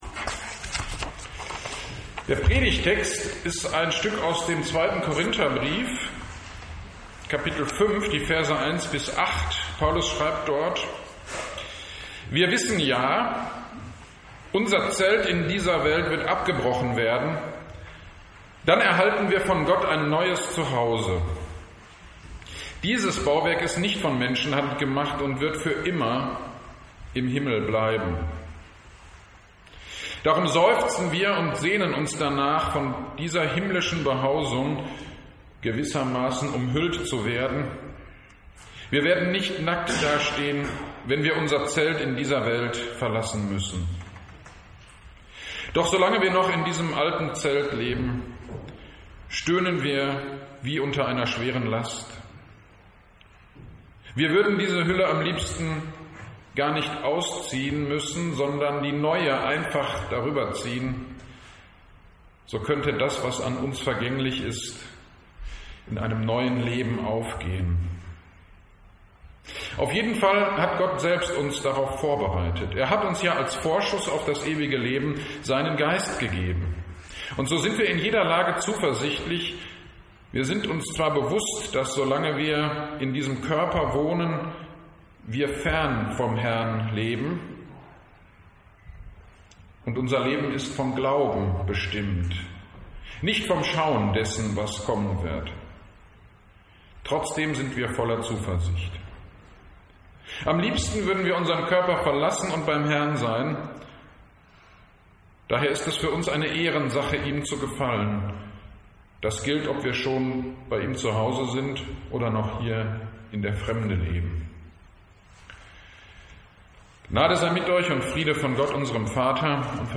Predigt zu 2. Korinther 5,1-10: Auferstehung - Kirchgemeinde Pölzig
Predigt-ueber-2.-Korinther-51-10.mp3